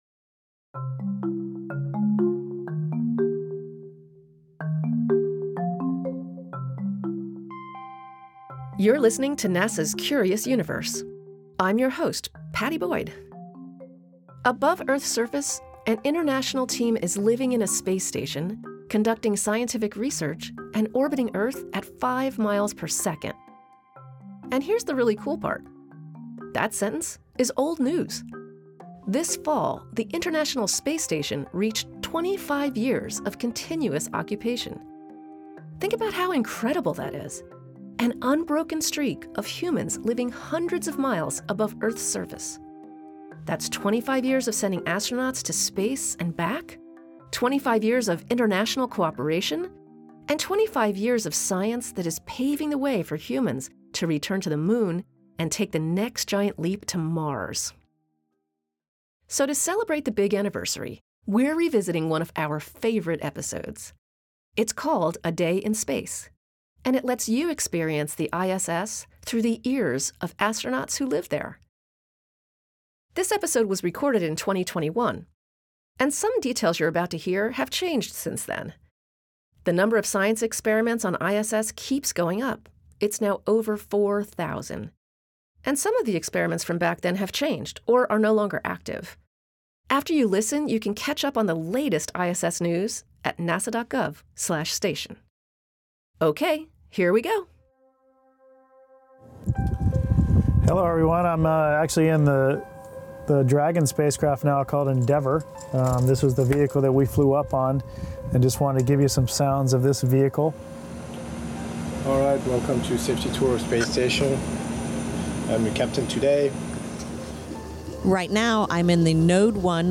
And it lets you experience the ISS through the ears of astronauts who live there.